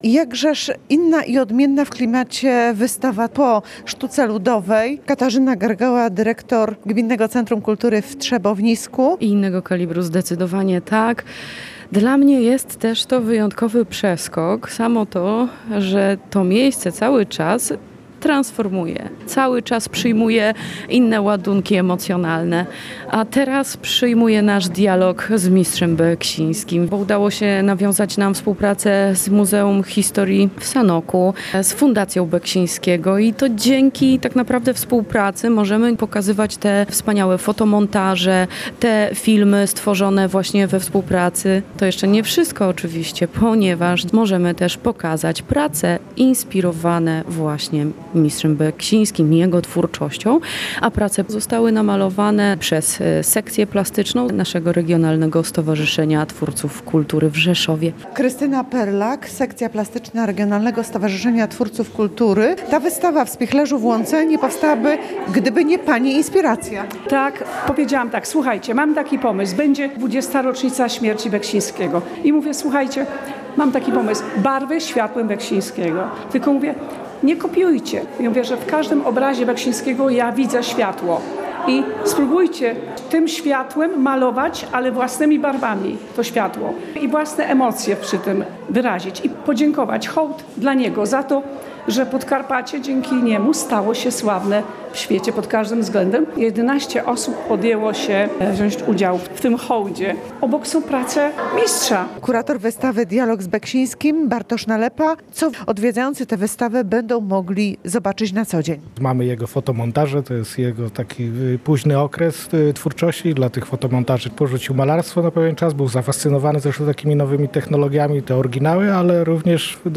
Wernisaż z muzyką na żywo